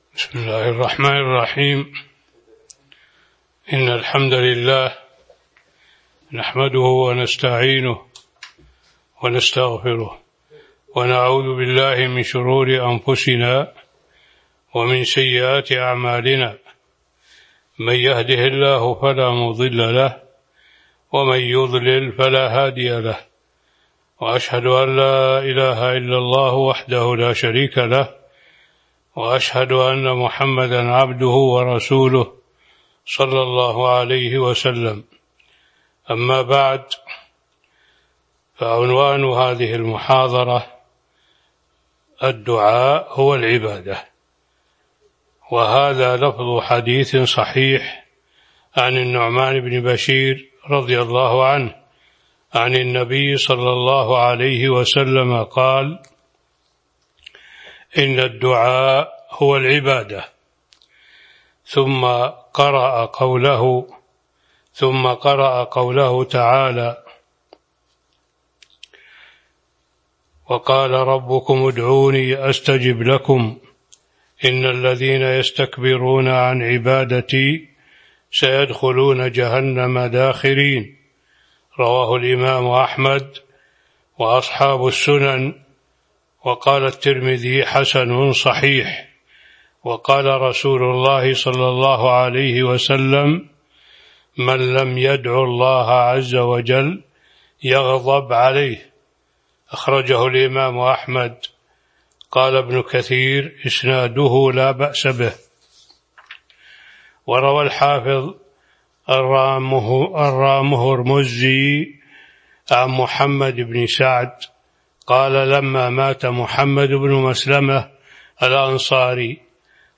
تاريخ النشر ١٦ ذو القعدة ١٤٤٢ هـ المكان: المسجد النبوي الشيخ